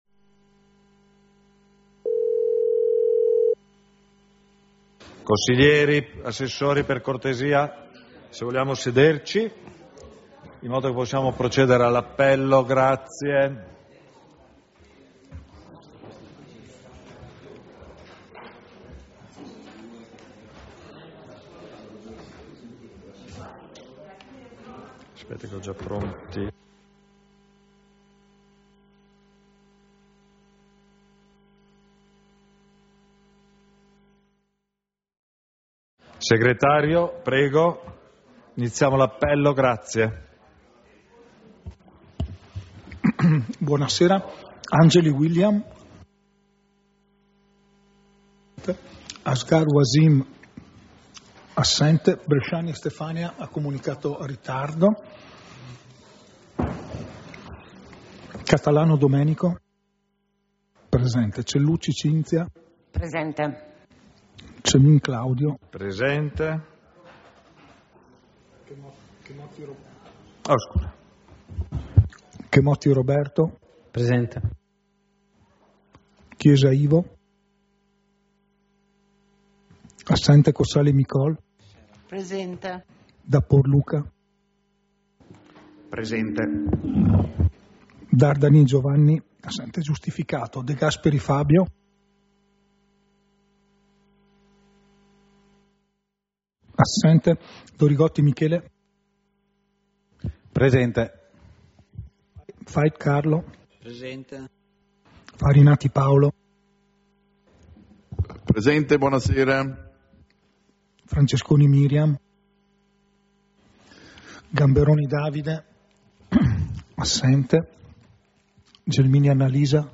Seduta del consiglio comunale - 29 ottobre 2025